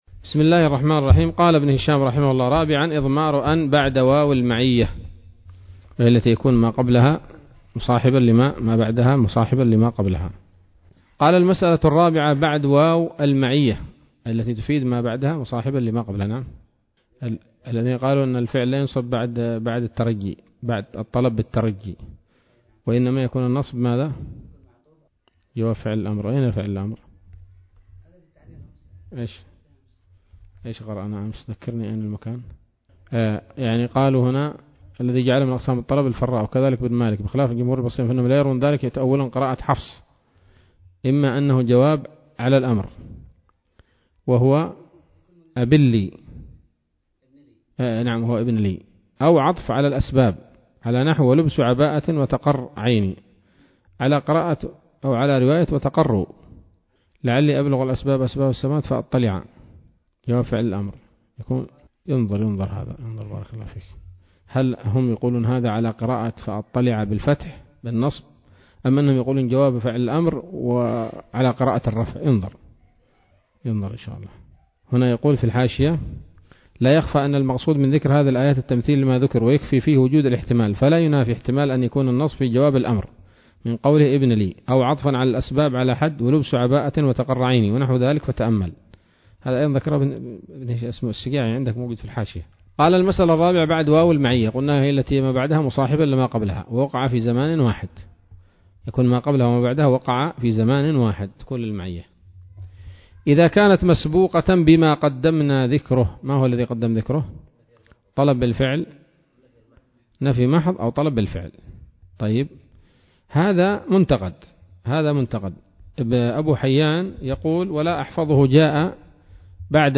الدرس الثالث والثلاثون من شرح قطر الندى وبل الصدى [1444هـ]